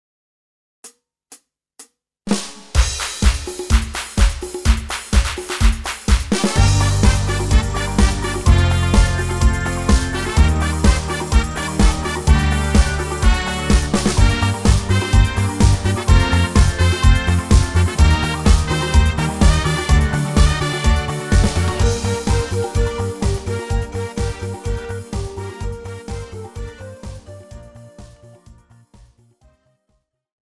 Žánr: Pop
BPM: 126
Key: Gm
MP3 ukázka s ML